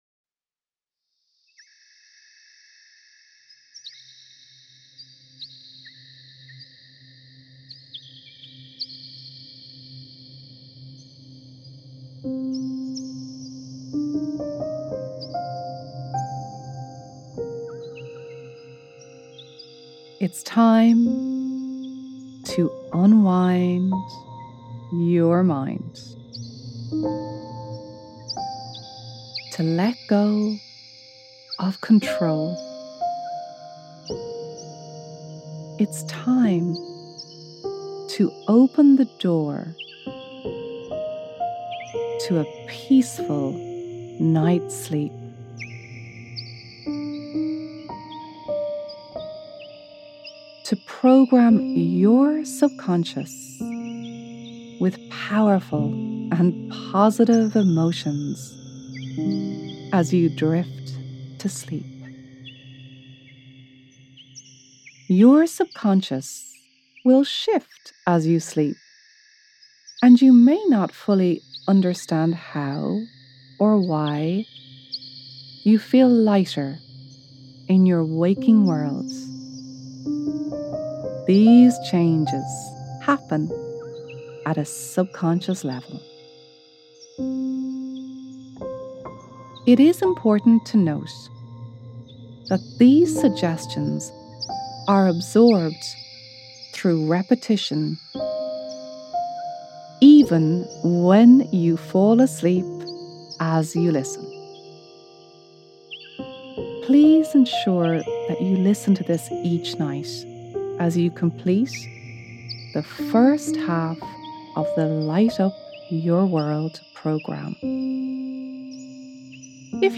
Free Hypnotherapy Audio for You All - Taken from 'Light Up Your World'